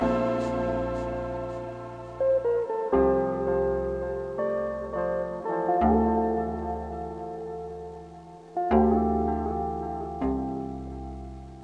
CASE 165BPM - FUSION.wav